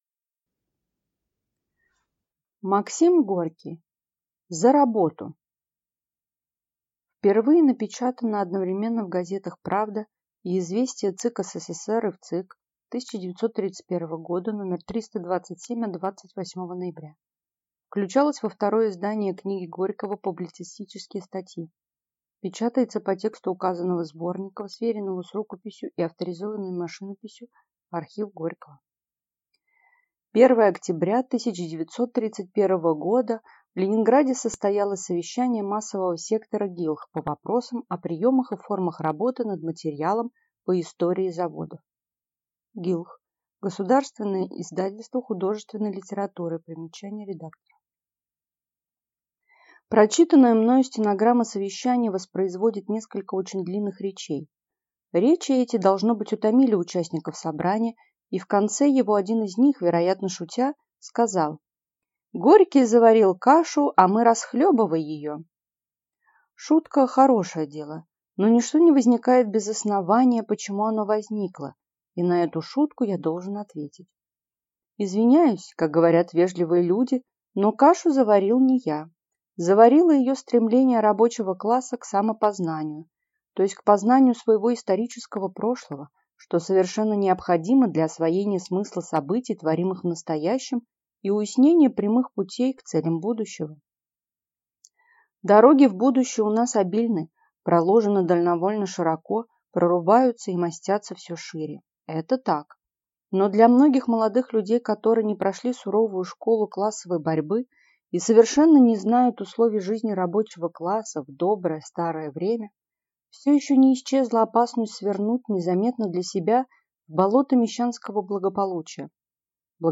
Аудиокнига За работу!